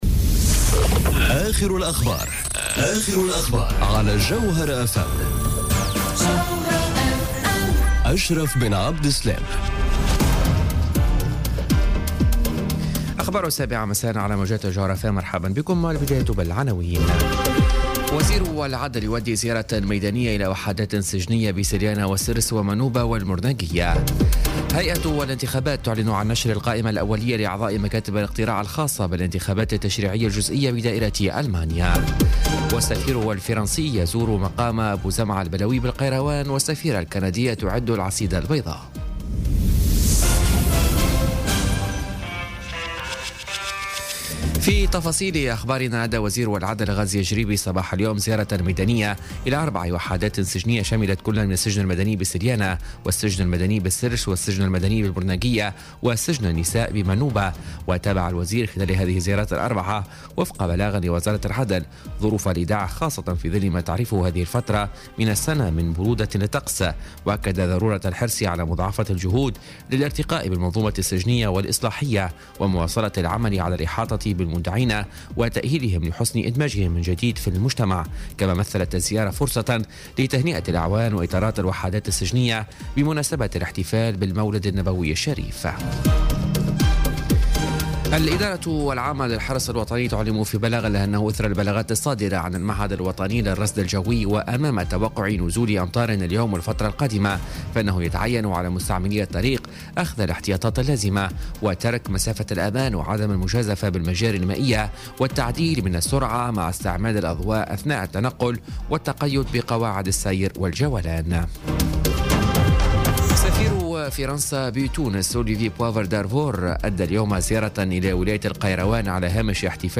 Journal Info 19h00 du 1er Décembre 2017